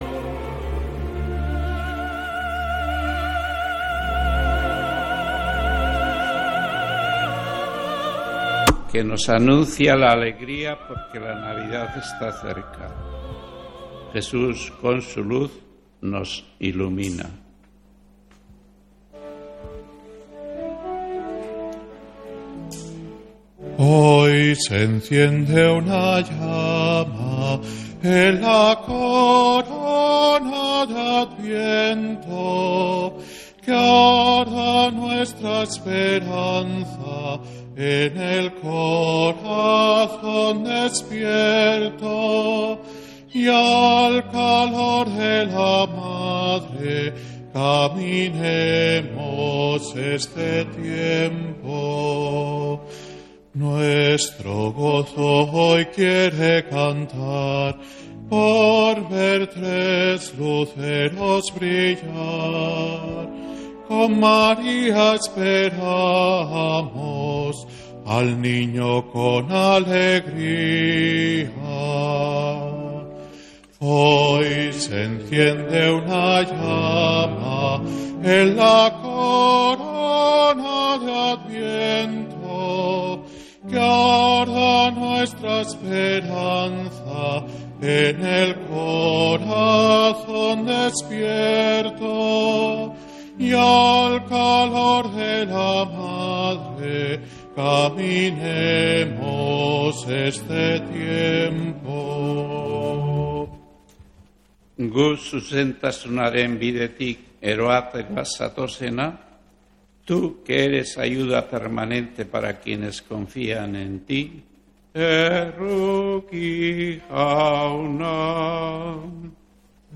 Santa Misa desde San Felicísimo en Deusto, domingo 15 de diciembre